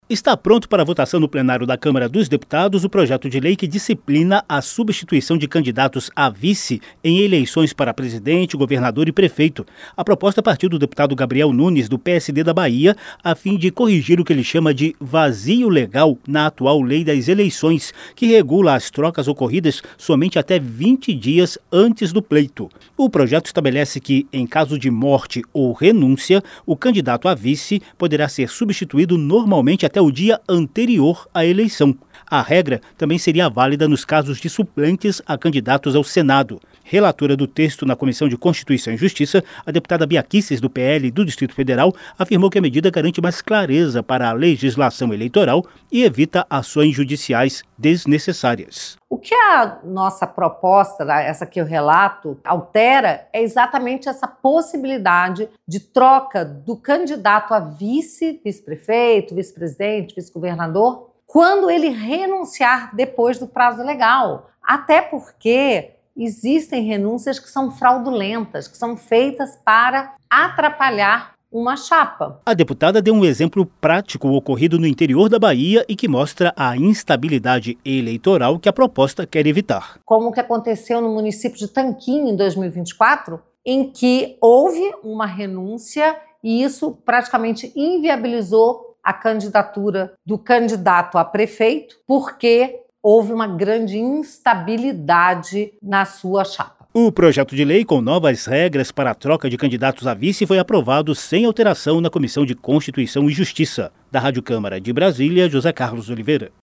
CÂMARA ANALISA NOVAS REGRAS PARA TROCA DE CANDIDATOS A VICE NAS ELEIÇÕES. O REPÓRTER